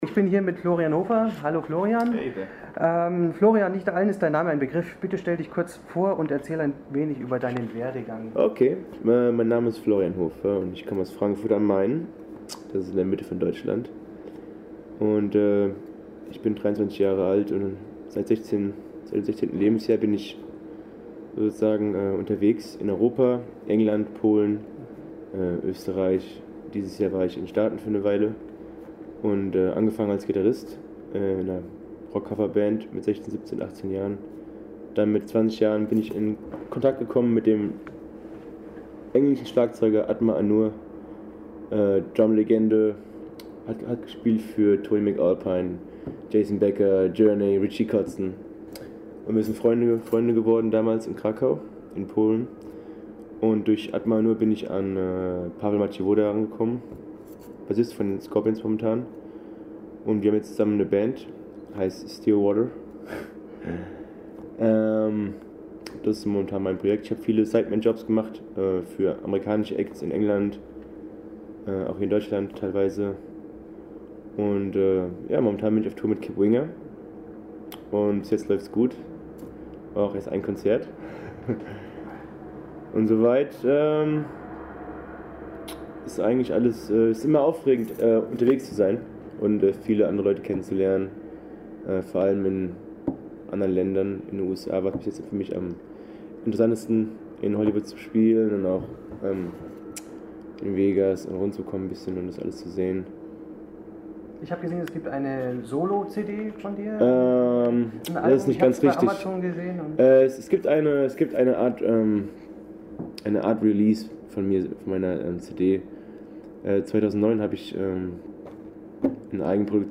Zusammen mit dem aktuellen bassisten von den Scorpions hat er eine Band gegründet.... mehr dazu im Interview: